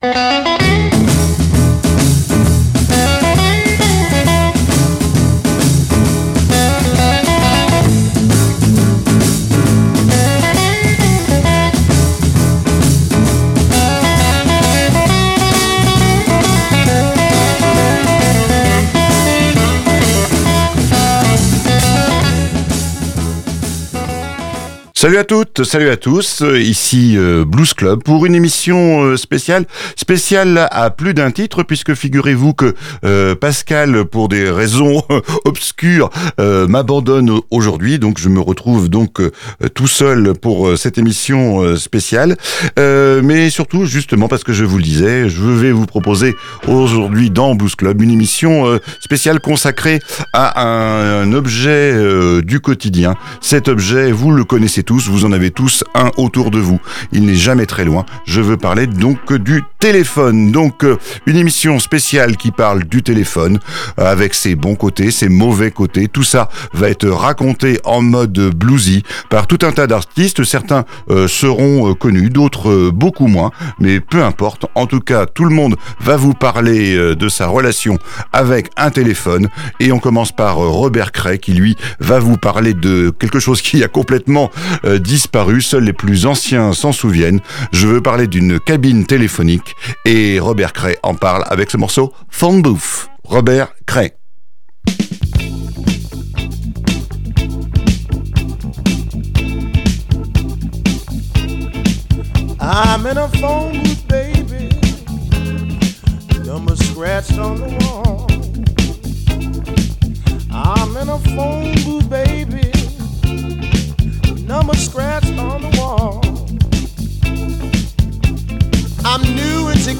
Le blues s’est également penché sur cet objet et tous les aléas qu’il peut déclencher…